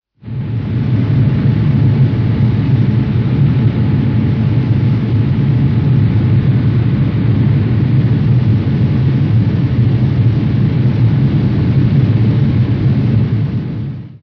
ore-refinery.ogg